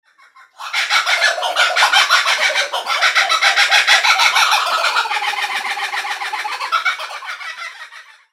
Crazy Chuckle Of Several Kookaburras - LAUGHING SOUND DROWNS CIA MOCKING BIRD LIES!
Crazy Chuckle Of Several Kookaburras Mp 3